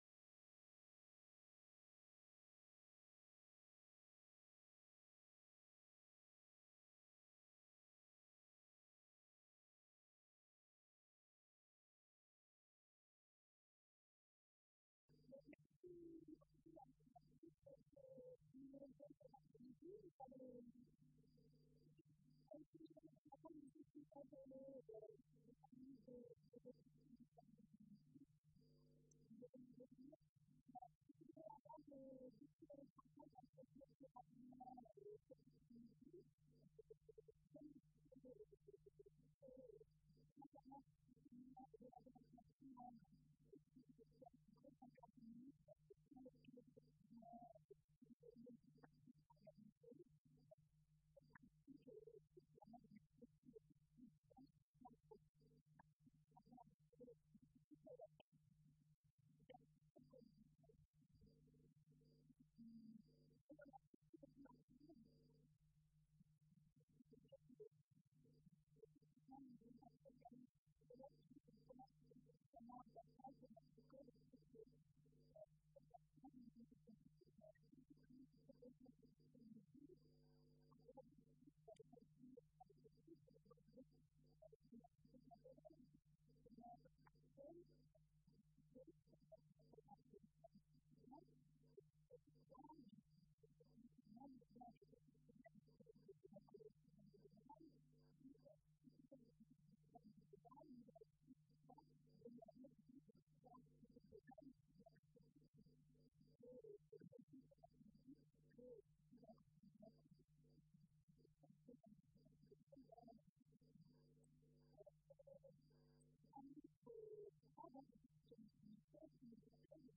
Colloque L’Islam et l’Occident à l’époque médiévale.